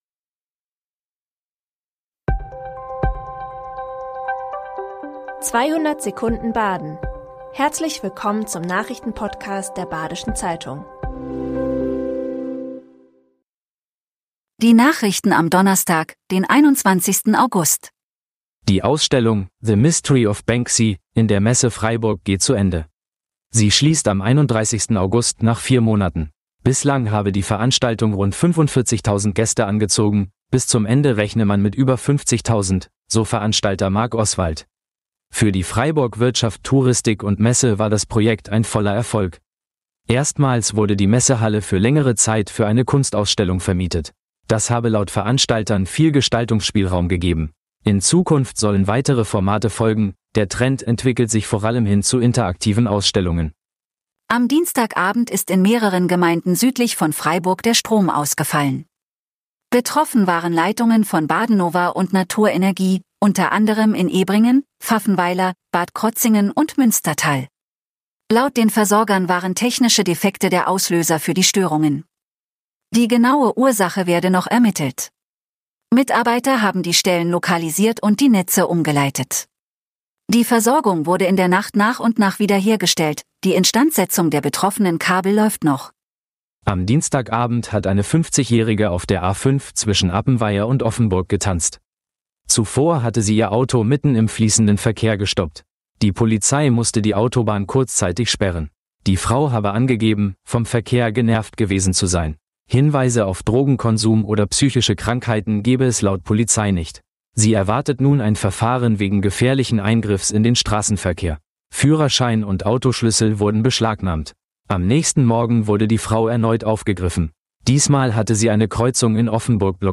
5 Nachrichten in 200 Sekunden.